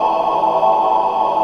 YETI VOX.wav